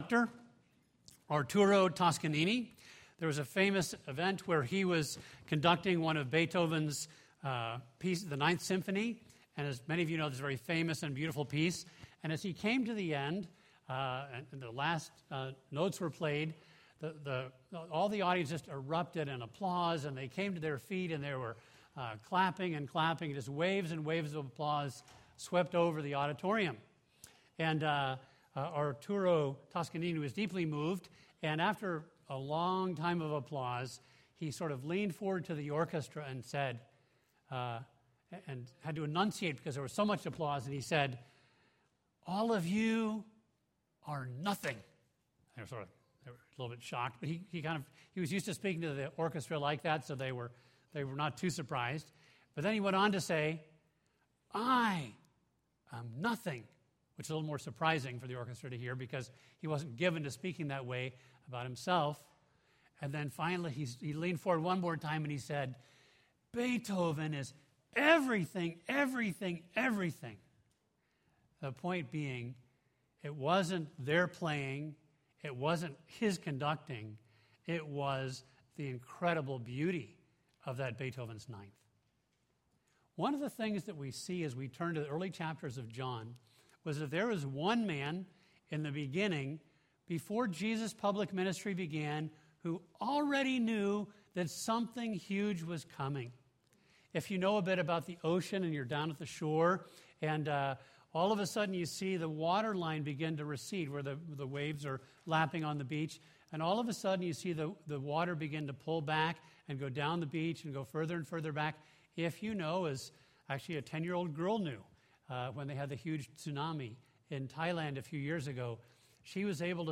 A message from the series "Gospel of John."